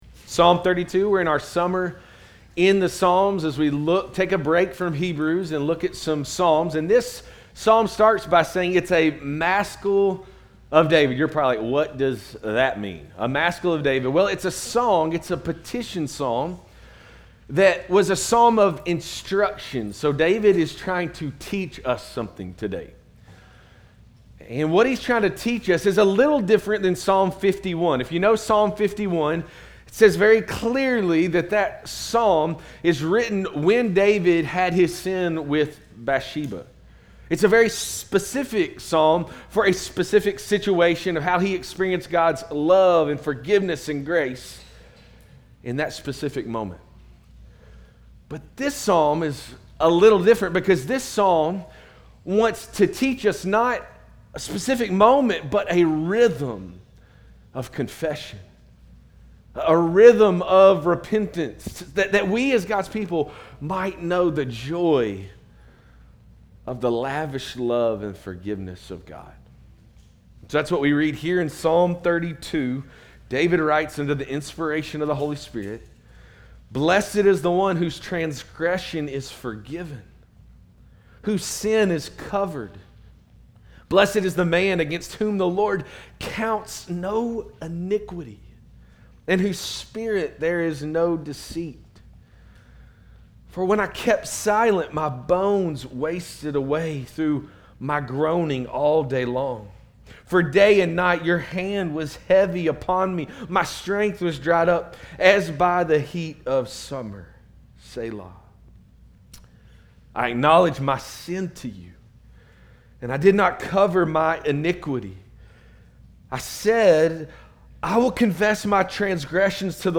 Christ Fellowship Sermons